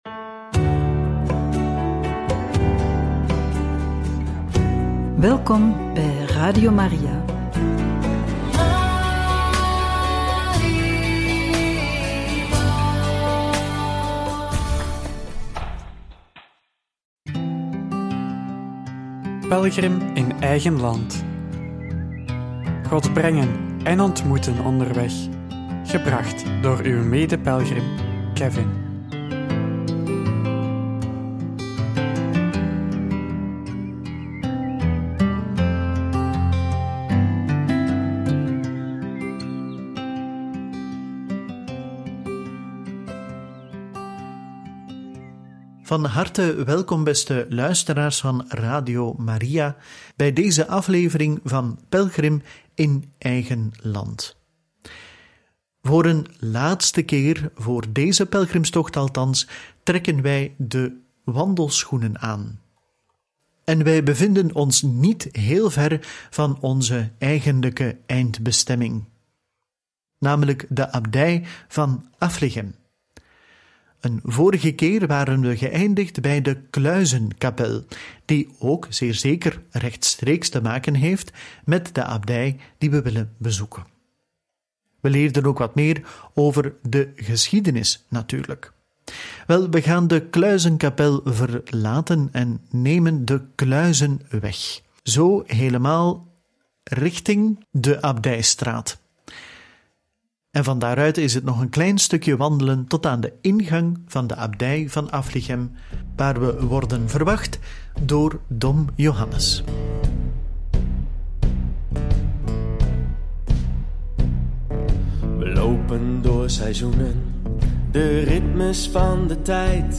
Op bezoek in de Abdij van Affligem